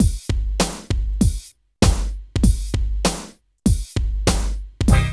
74 DRUM LP-L.wav